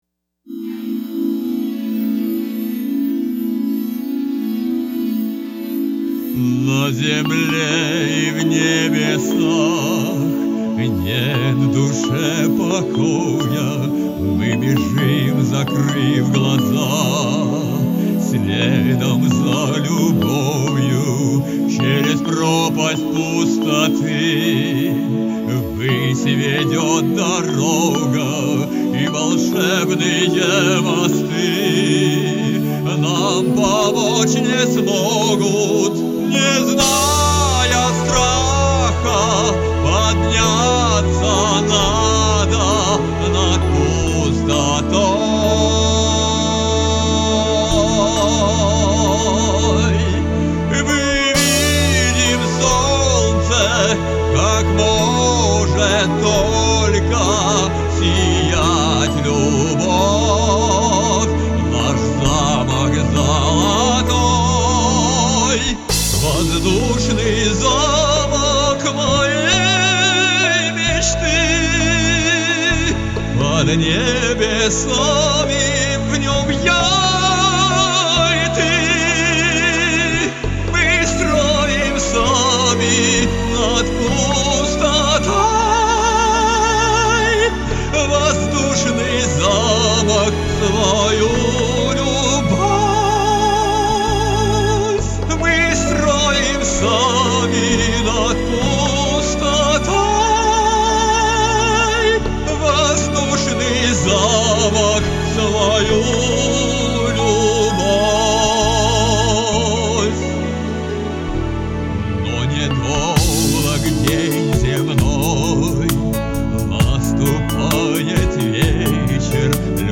Правда чистого звука в караоке не бывает)))